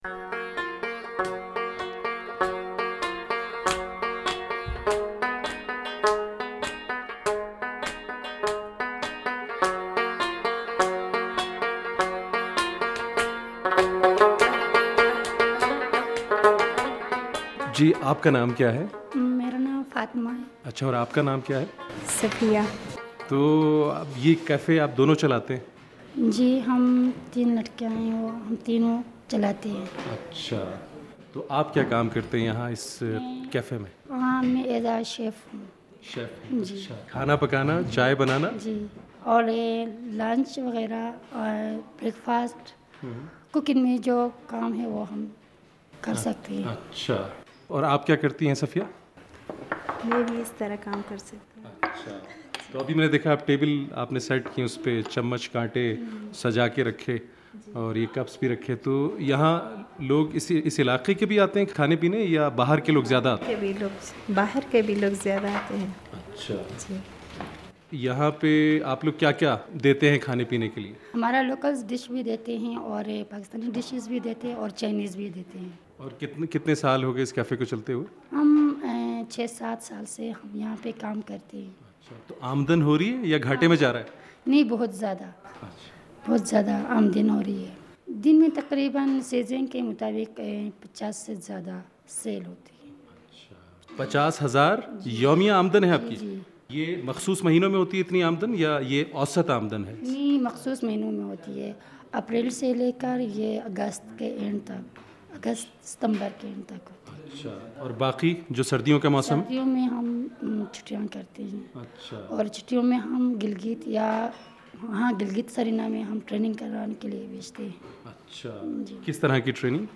سنیے ان کی رپورٹ جو موسیقی سے شروع ہوتی ہے۔